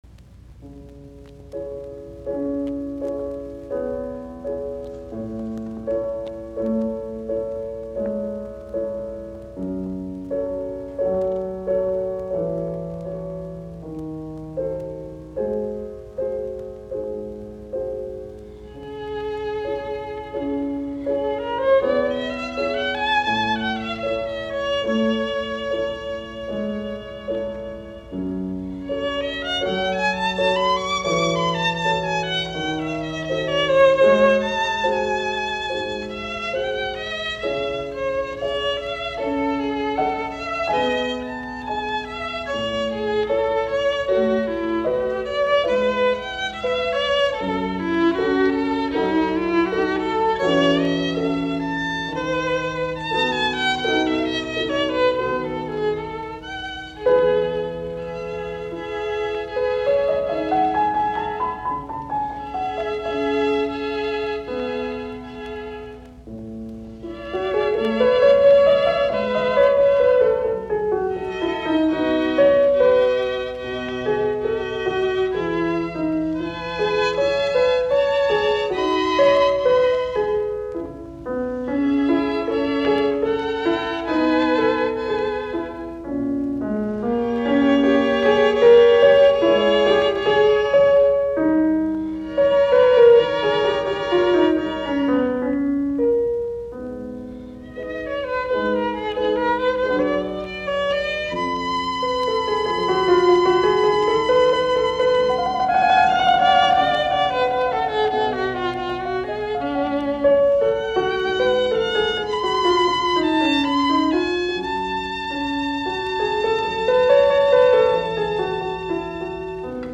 dc.contributor.otherMenuhin, Yehudi, viulu.
dc.contributor.otherKentner, Louis, piano.